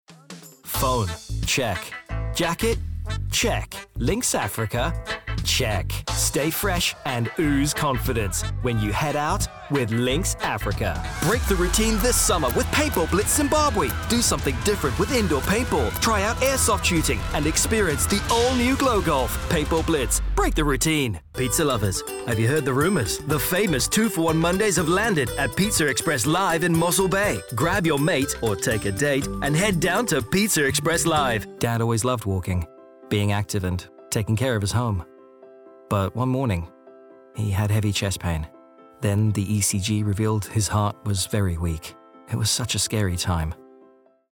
Inglês (sul-africano)
Descrito como alguém com uma voz cálida e clara...
Confiável
Esquentar
Amigáveis